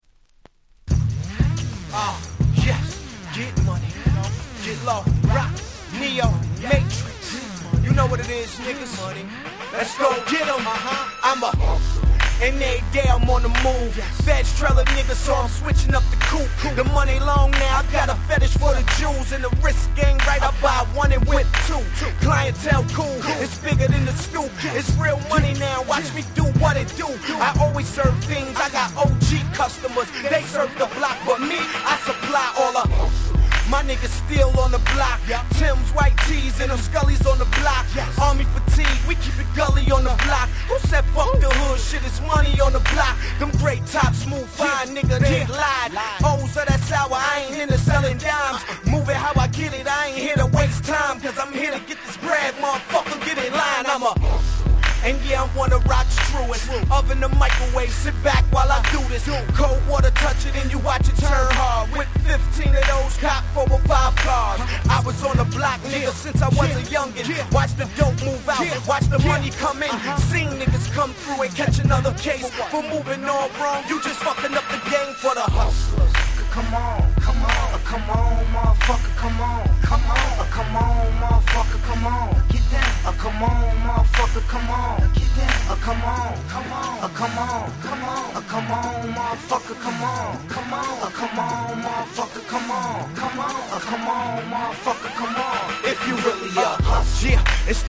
08' Nice Hip Hop !!